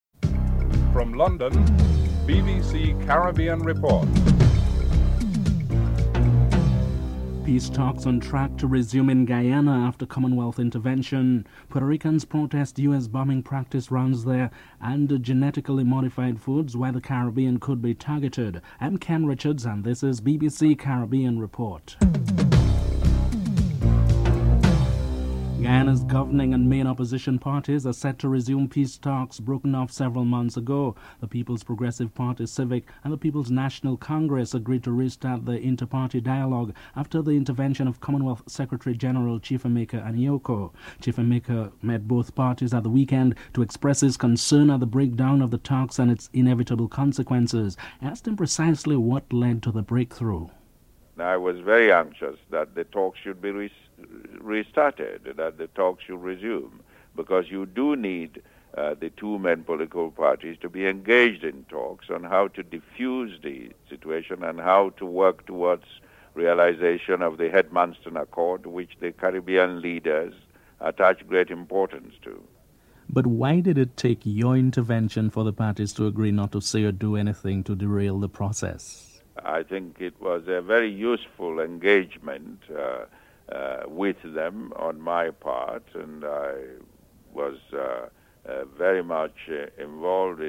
9. Final preparations are underway for hosting World Cup Cricket and organisers are looking for ways to raise revenue. In the first in a series of reports, BBC correspondent explores the significance of raising revenue for the World Cup cricket teams (13: 22 – 15: 32)